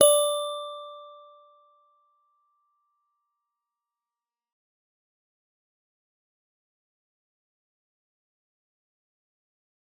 G_Musicbox-D5-f.wav